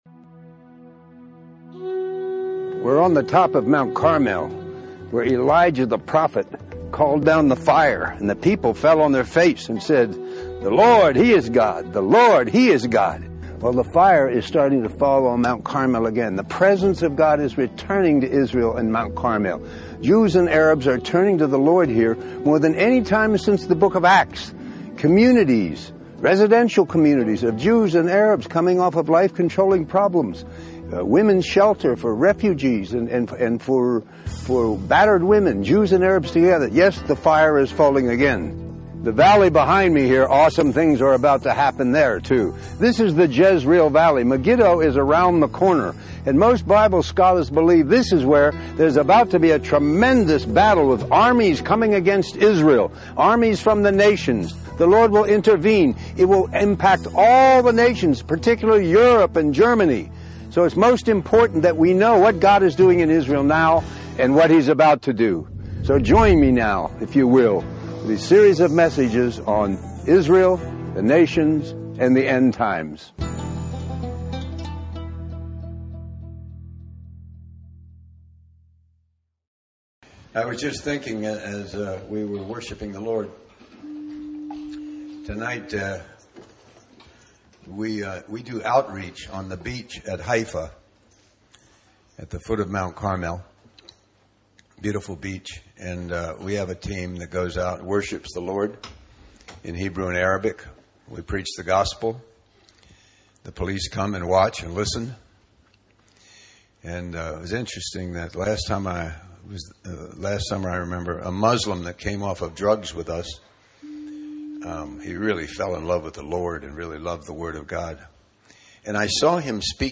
In this sermon, the preacher emphasizes the importance of boasting only in the cross of Jesus Christ.